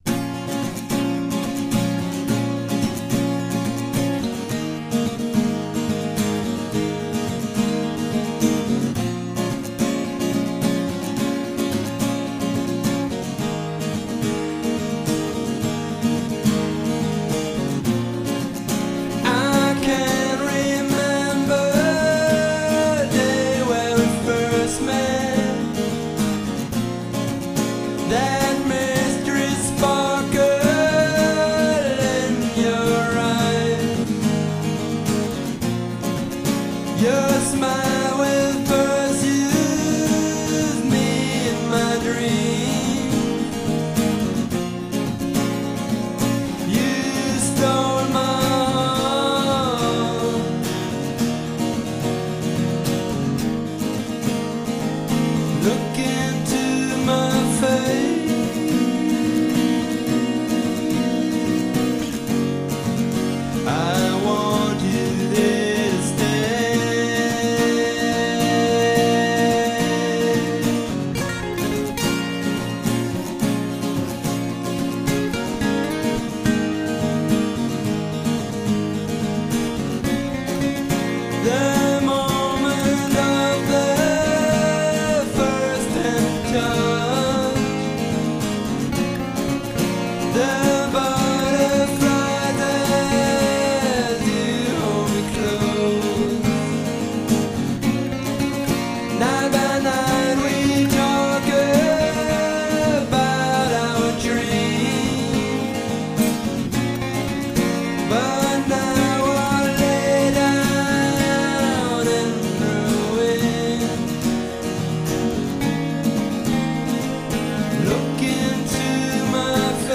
akustisch arrangierte eigene Songs